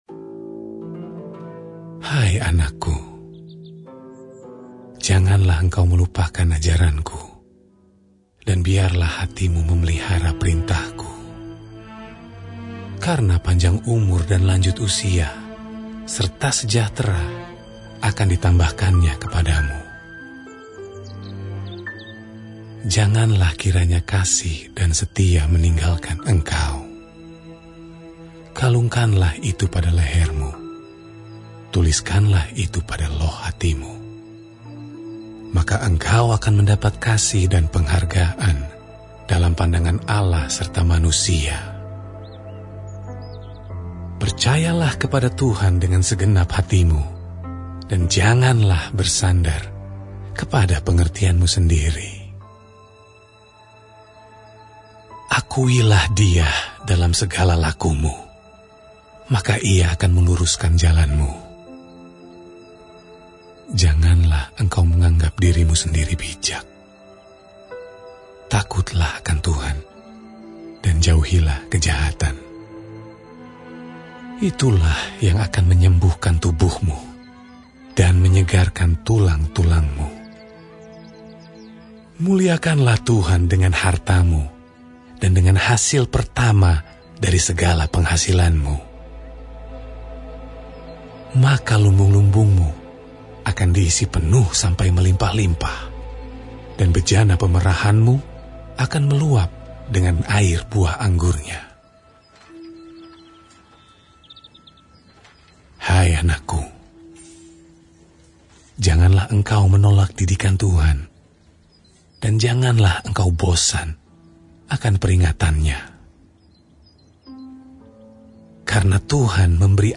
Audio Bacaan Alkitab Setahun Sabtu, 5 Juli 2025 – Amsal 1 s.d 3